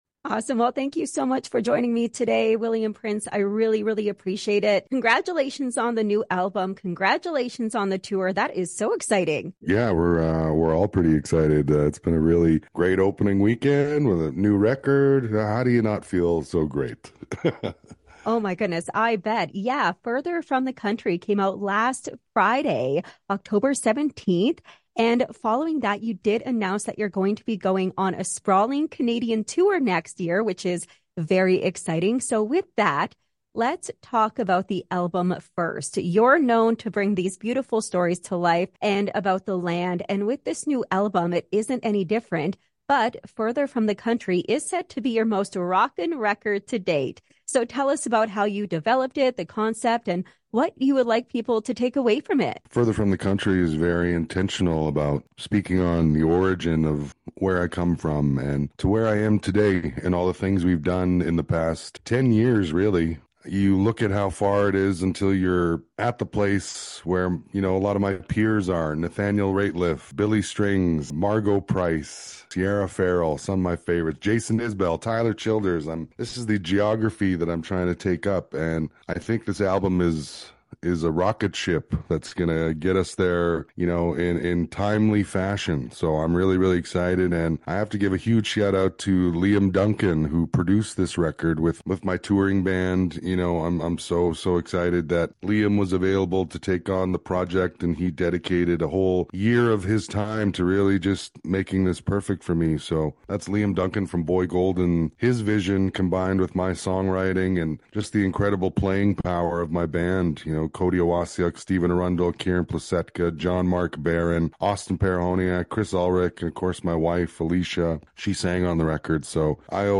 William spoke with me about the album and the tour BELOW: